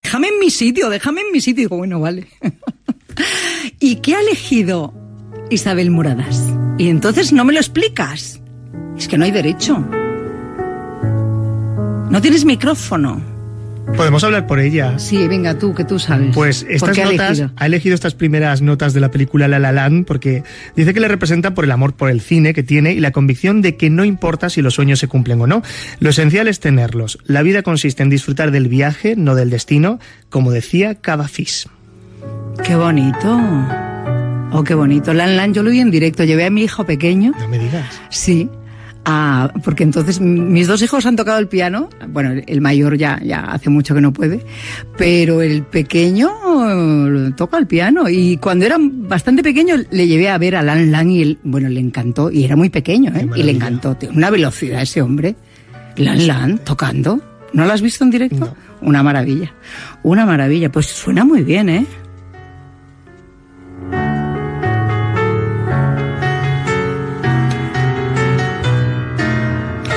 Entreteniment
Gemio, Isabel